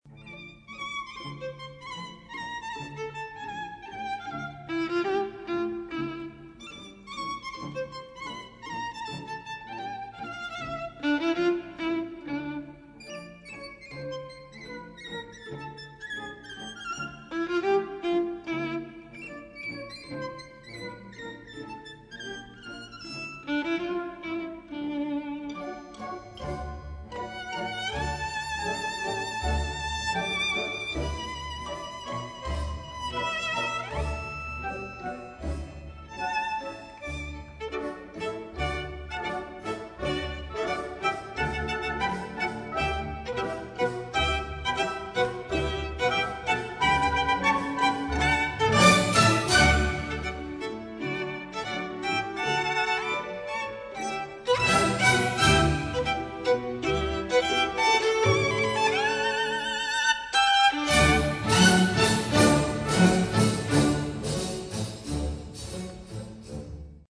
Leonid Kogan,
USSR State Symphony Orchestra
for Violin and Orchestra, Op.25
Composer: Pablo Martin Meliton de Sarasate